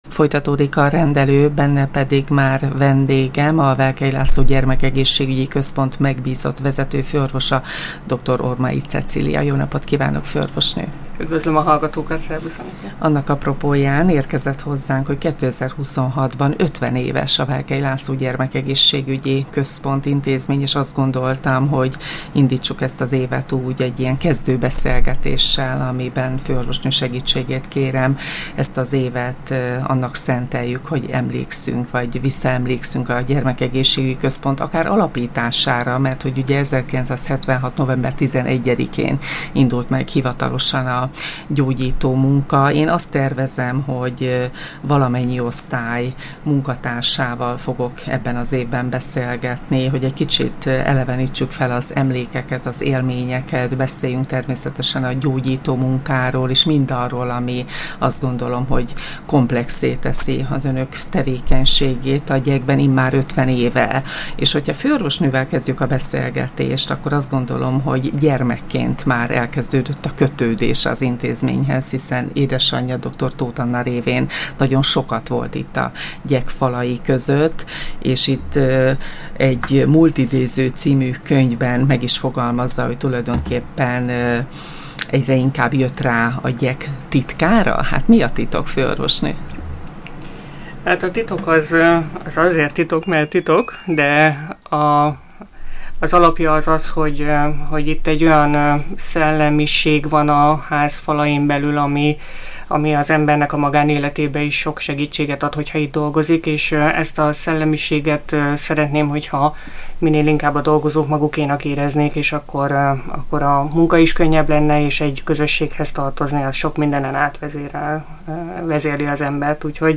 Múltidézés, a jelen aktualitásai és a jövő elképzelései, tervei is terítékre kerültek a beszélgetésben.